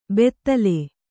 ♪ bettale